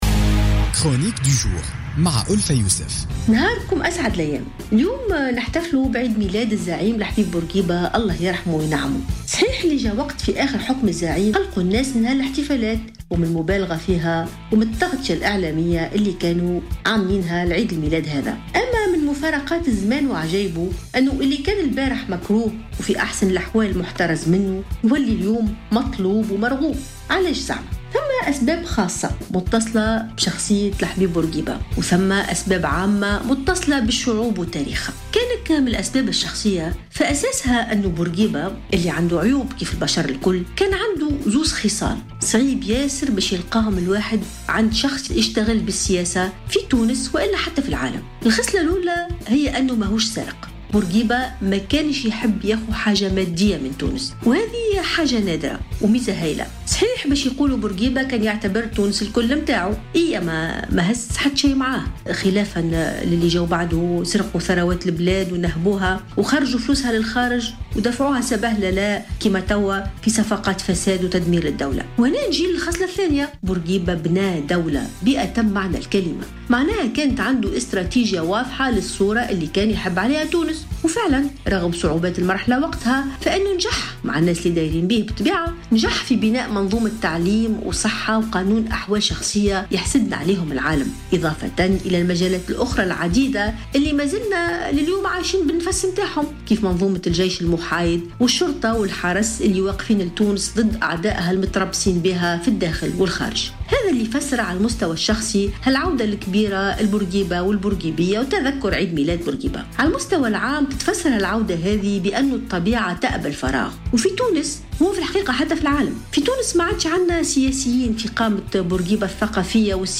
تطرّقت الكاتبة ألفة يوسف في افتتاحيتها الصوتية لـ "الجوهرة أف أم" اليوم الخميس إلى ذكرى ميلاد الزعيم الحبيب بورقيبة الذي يتذكّر التونسيون عموما ميلاده يوم 3 أوت من كل عام.